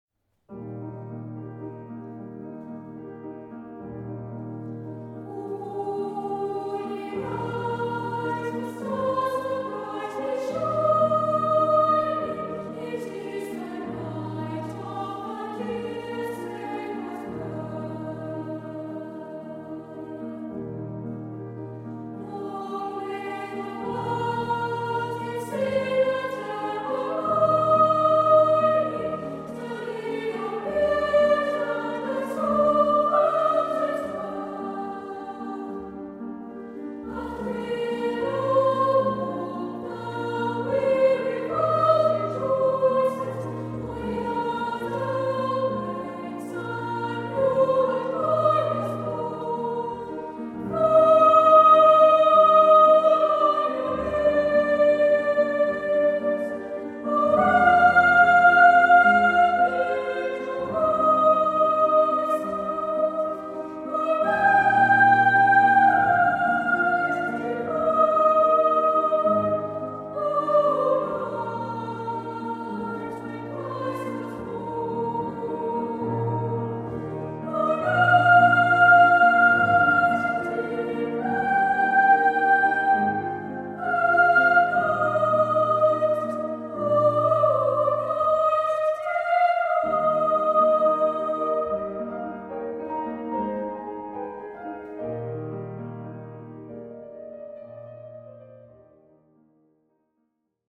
Capture the essence and innocence of the Christmas season with all your favourite carols being sung by a Children’s Choir – the enchanting sounds of Piccolo Lasso – one of Ireland’s leading choral groups.  With stunning boy soprano solos on O Holy Night, Walking in the Air, this is the Christmas choral repertoire performed to the highest standards.